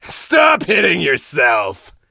monster_stu_kill_vo_09.ogg